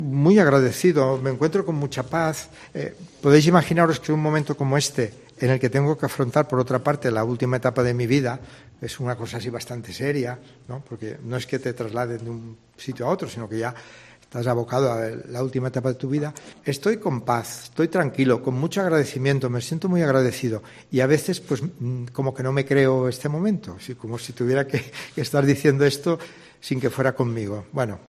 José Vilaplana, Administrador Apostólico en Huelva